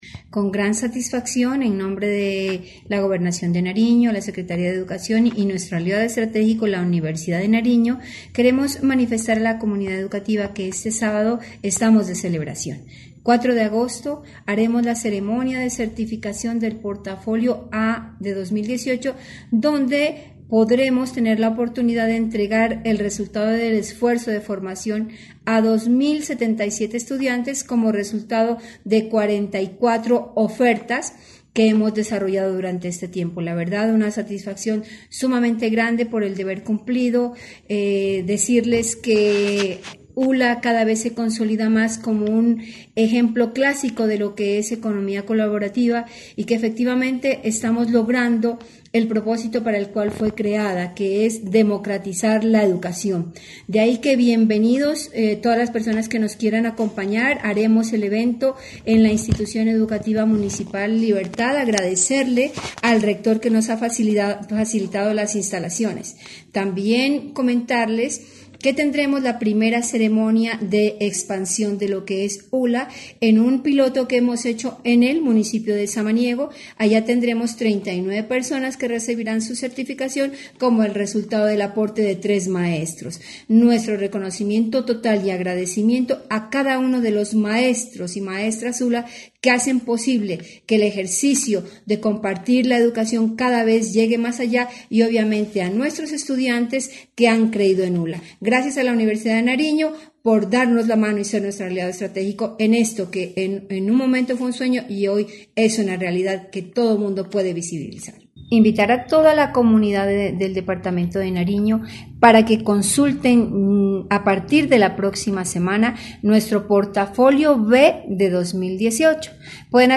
DORIS_MEJA_BENAVIDES_-_SECRETARIA_DE_EDUCACIN_DE_NARIO.mp3